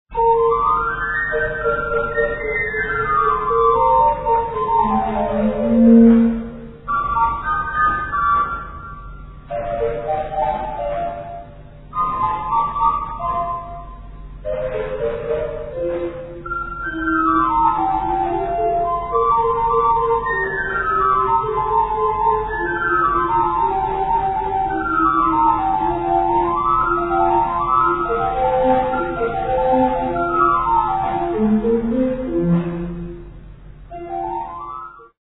for organ, in B flat major